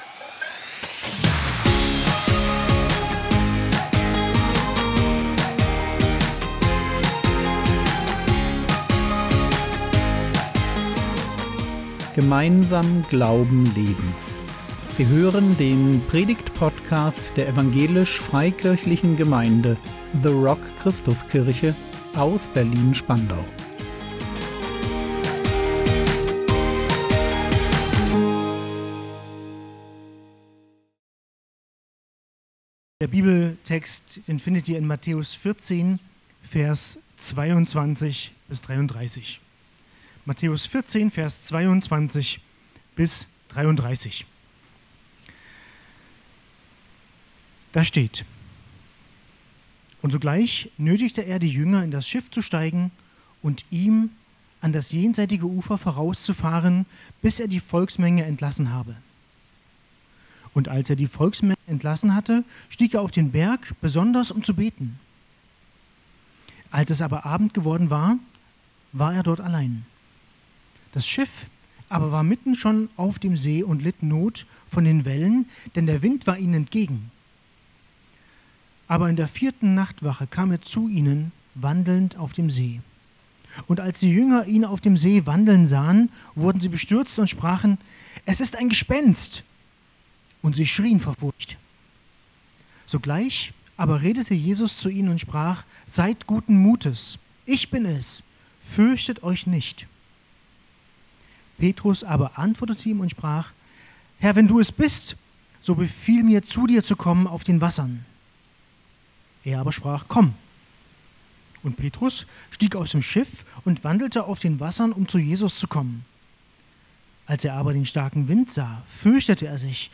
Vertrauensschritte im Sturm | 25.01.2026 ~ Predigt Podcast der EFG The Rock Christuskirche Berlin Podcast